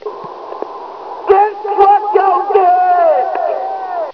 Krillin saying distructo disc